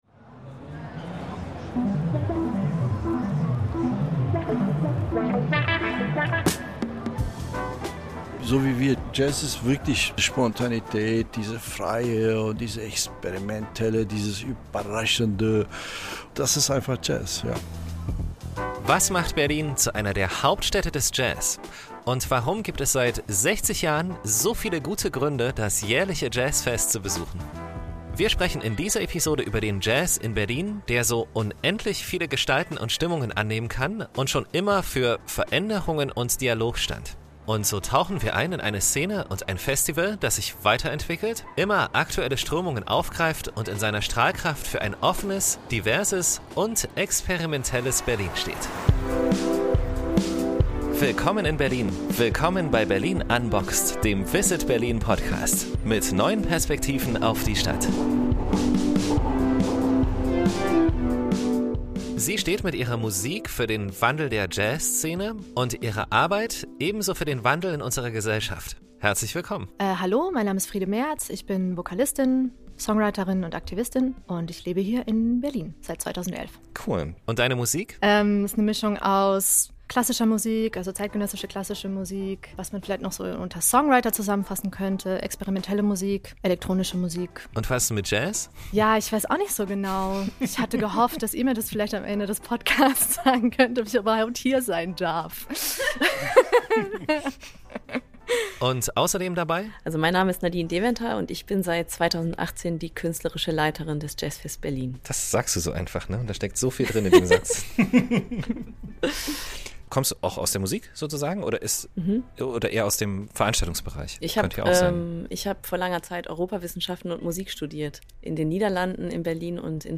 Dort sprechen wir mit zwei Frauen, die Berlins Jazzszene maßgeblich mitprägen und in ihrer Rolle auch ganz besonders für den Wandel in diesem so lebendigen Genre stehen.